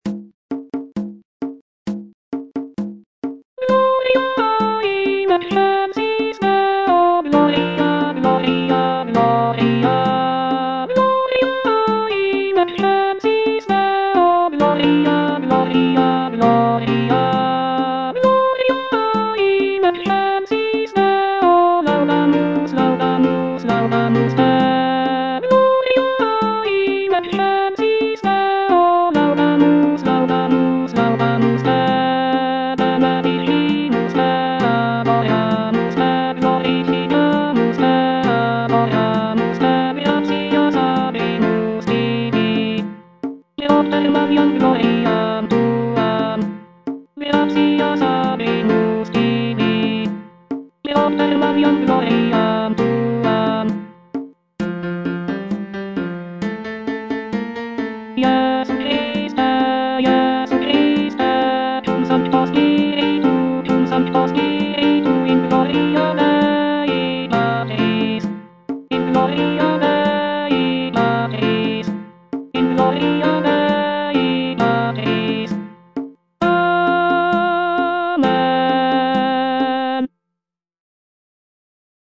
Alto (Voice with metronome) Ads stop